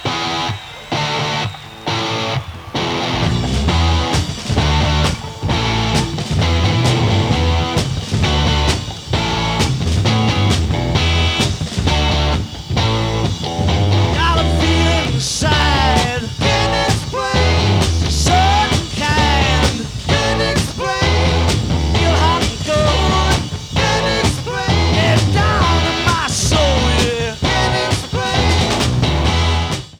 2021 Remix (Stereo)
2021 Remix (HD Sample)